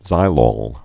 (zīlôl, -lōl)